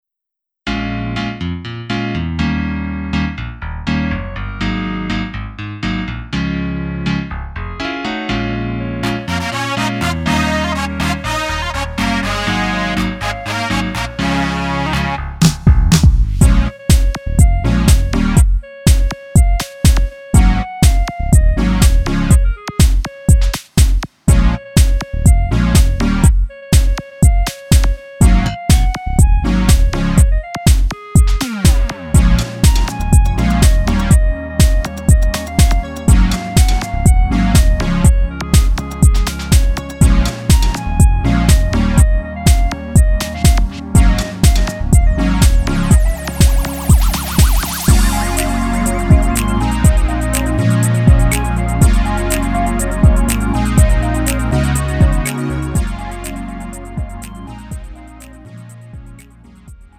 음정 -1키 3:45
장르 가요 구분 Lite MR